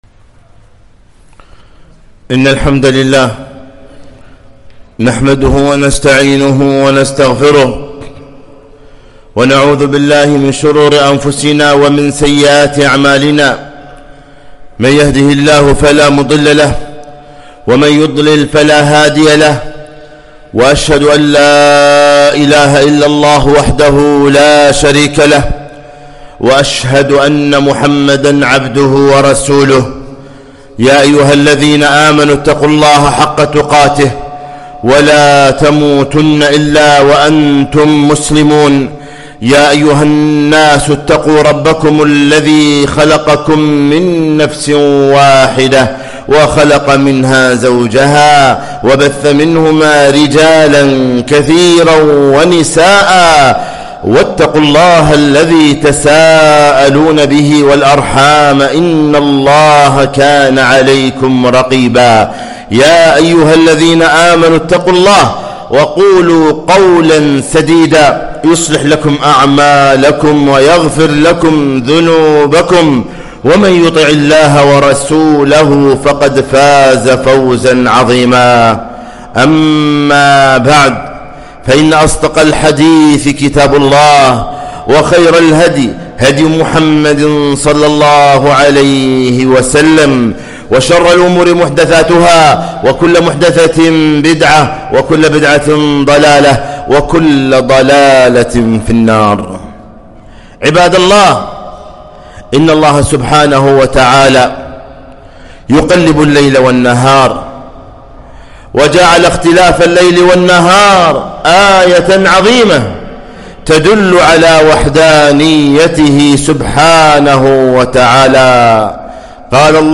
خطبة - ( مرحباً بالشتاء)